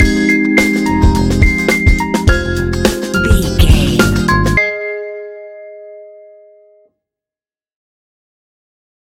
Ionian/Major
G♯
laid back
Lounge
sparse
chilled electronica
ambient